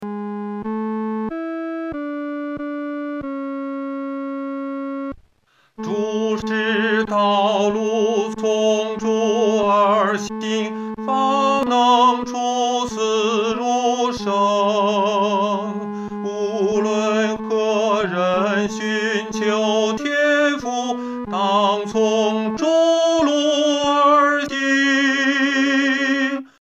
男高
本首圣诗由石家庄圣诗班录制